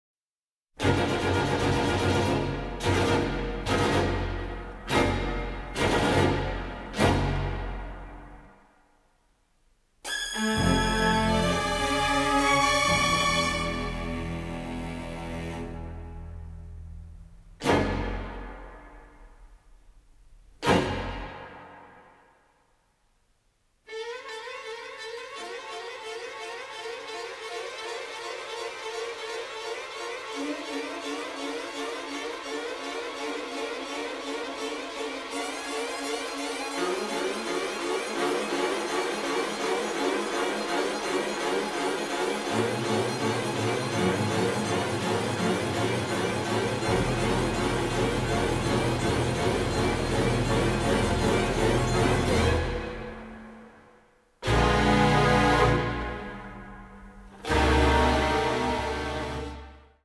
bizarre sci-fi score
electronic music